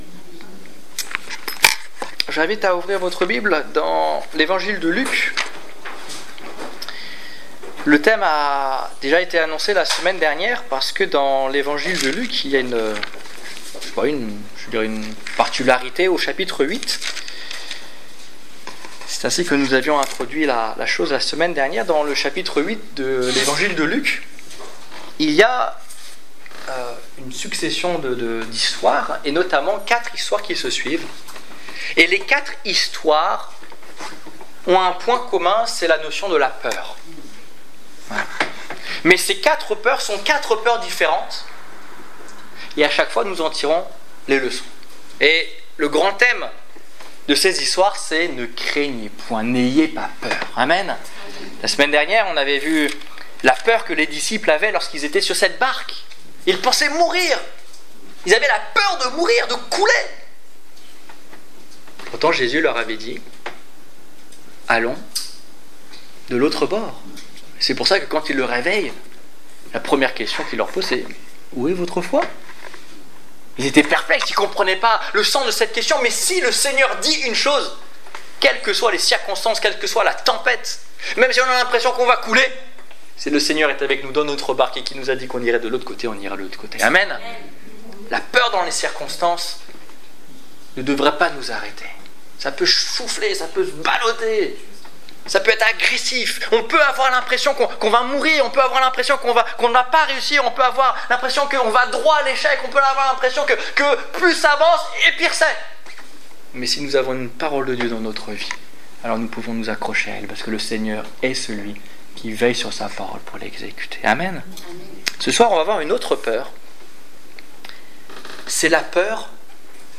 Évangélisation du 4 septembre 2015